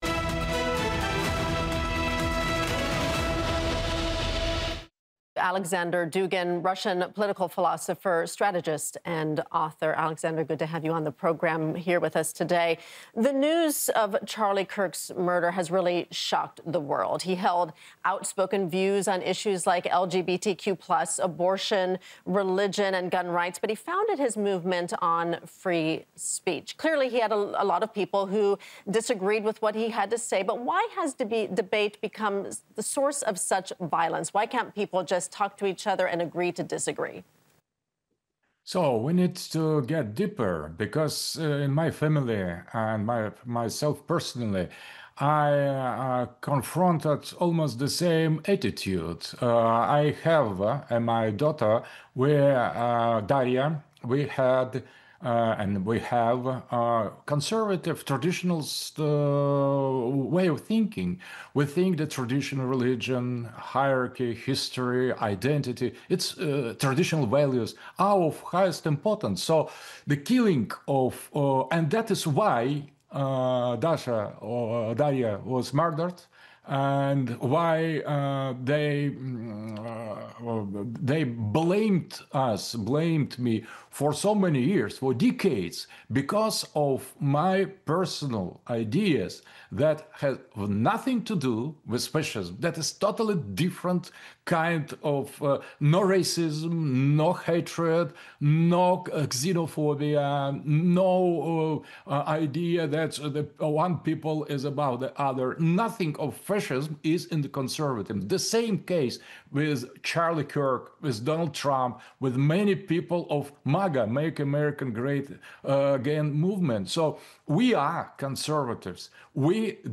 RT sat down with Aleksandr Dugin, Russian political philosopher, strategist and author, to talk about the murder of Charlie Kirk and why debate has become the source of such violence.